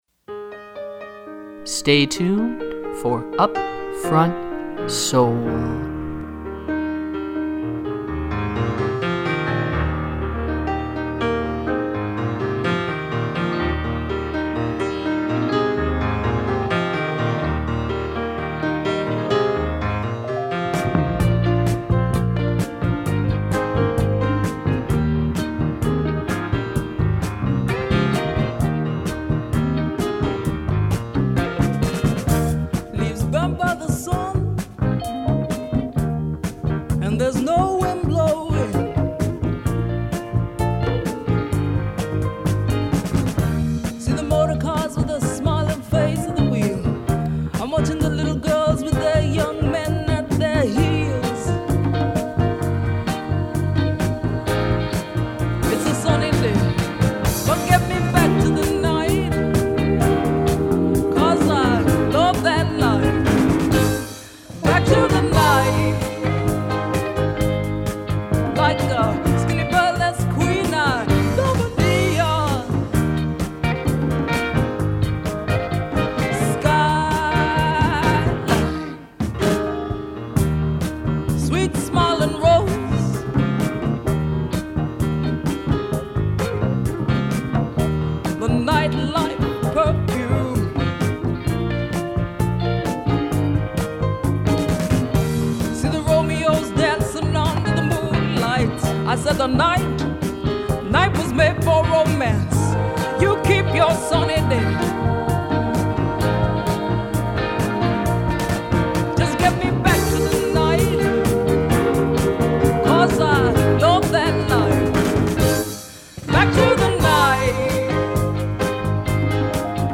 Funk, soul, and jazz
120 minutes of soulful sounds to which you may get down. Program Type: Weekly Program Speakers